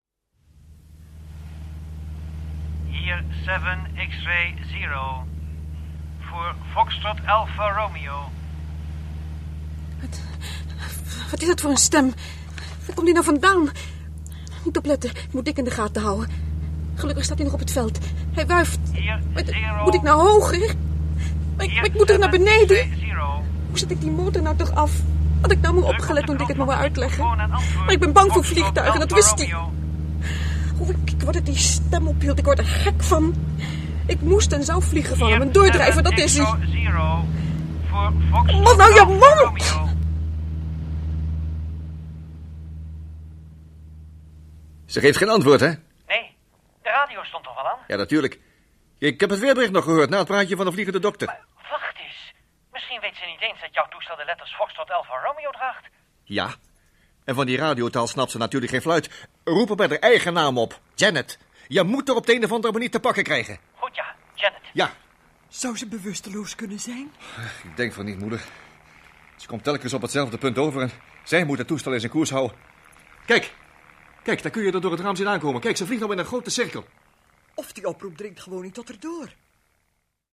Dit hoorspel is wederom op audio-cd uitgebracht en hierdoor kan men het op elke cd-speler beluisteren, ook zonder MP3.